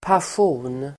Uttal: [pasj'o:n]